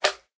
sounds / mob / slime / small1.ogg